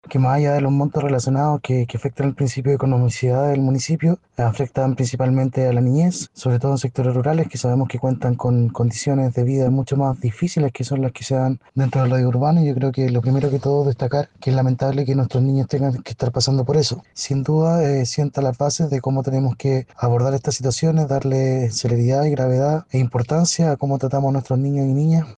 Alex Santander, concejal del Frente Amplio, argumentó que hubo una falta de diligencias por parte de la municipalidad, además de apuntar hacia la debilidad del cuidado de la niñez en sectores rurales.